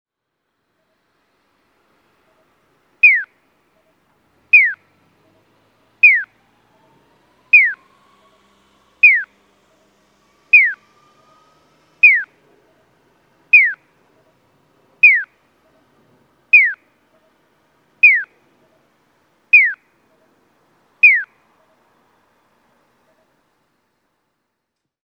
宇治浦田町交差点(三重県伊勢市)の音響信号を紹介しています。